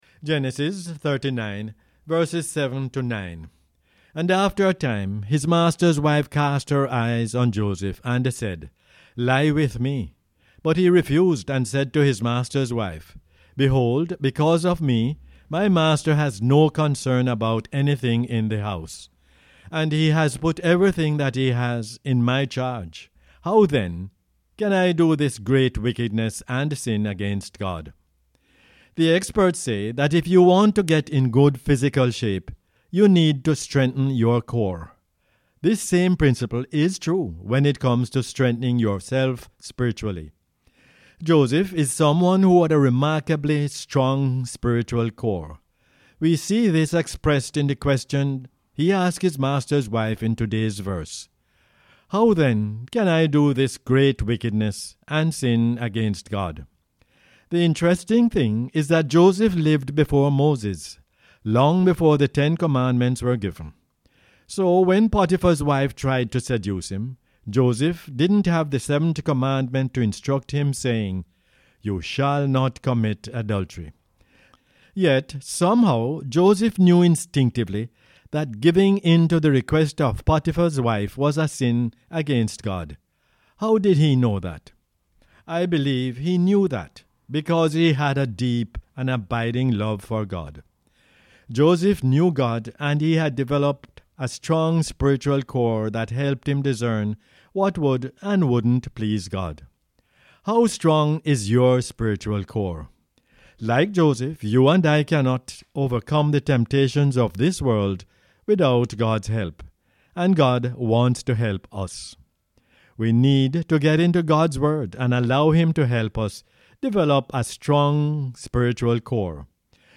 Genesis 39:7-9 is the “Word For Jamaica” as aired on the radio on 28 January 2022.